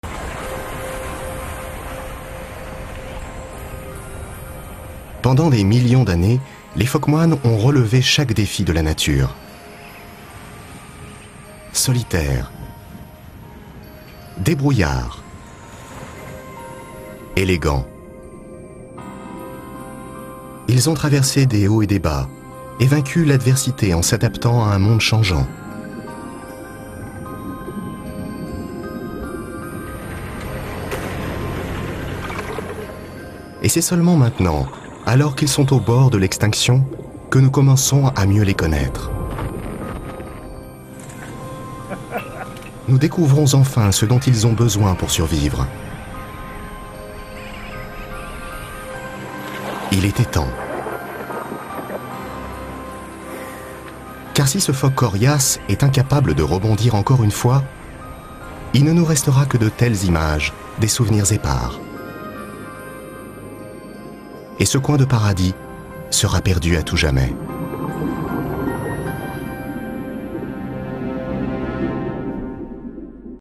Prestation voix-off sensible et dramatique pour "Les phoques moines d'Hawaï"
Voix sensible et élégant.
Diffusé sur France 5 et enregistré chez MFP.
Ma voix, qui oscille entre un ton médium et grave, a été choisie pour ce projet. J’ai modulé les tons pour être à la fois sensible, doux, élégant, posé et dramatique, afin d’évoquer au mieux la vie et les défis de ces phoques moines.